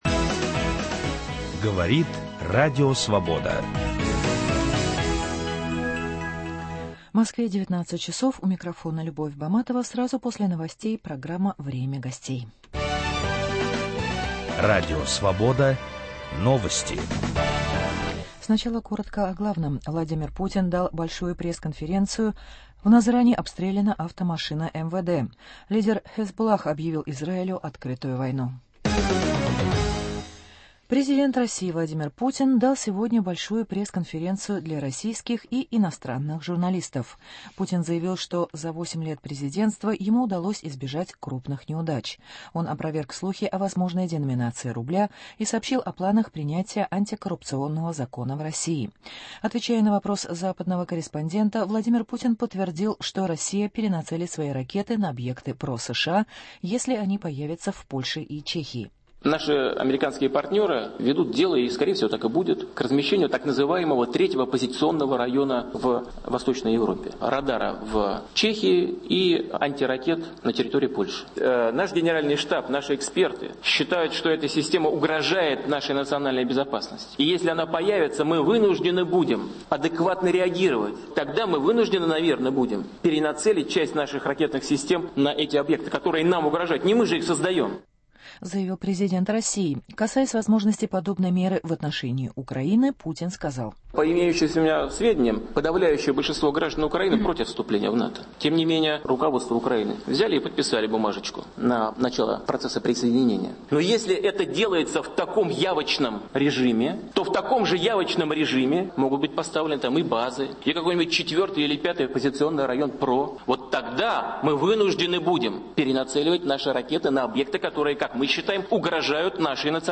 О президентских выборах, о российском кинематографе и телевидении, о том, что происходит в стране и Петербурге - размышляет известный кинорежиссер Владимир Бортко.